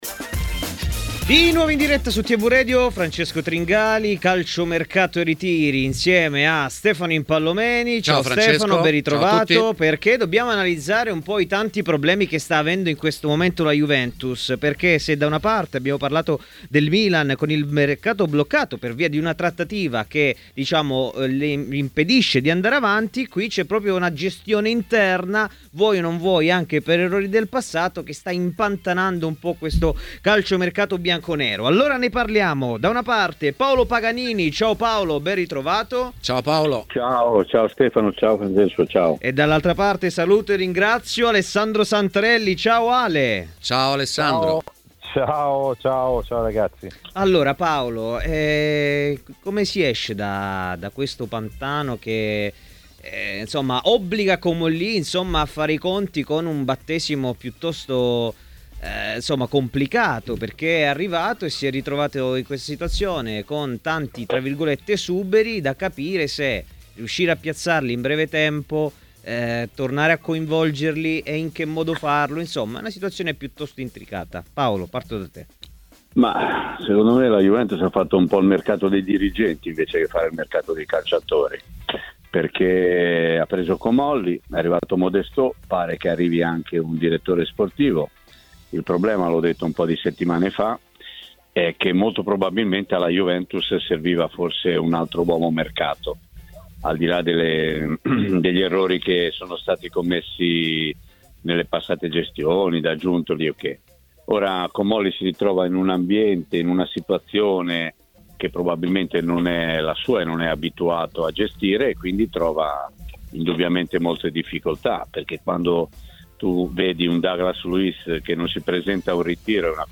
Calciomercato e Ritiri, trasmissione di TMW Radio.